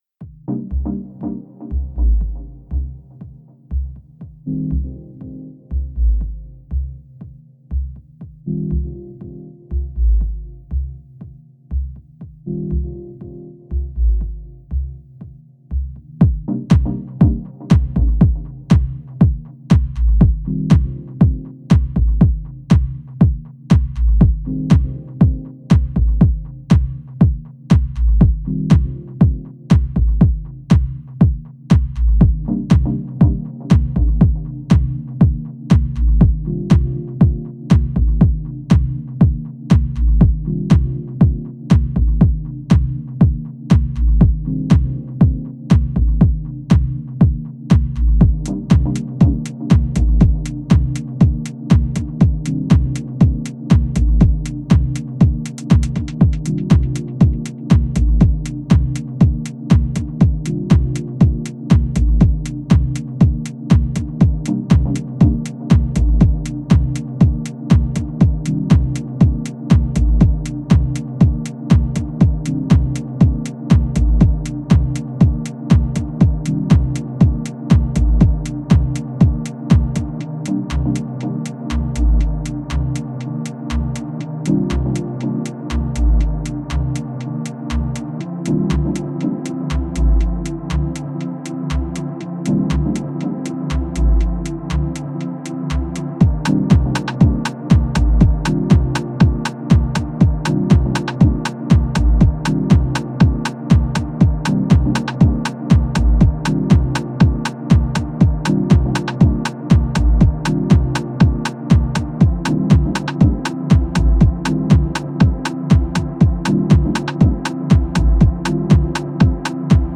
Genre: Deep Techno/Ambient/Dub Techno.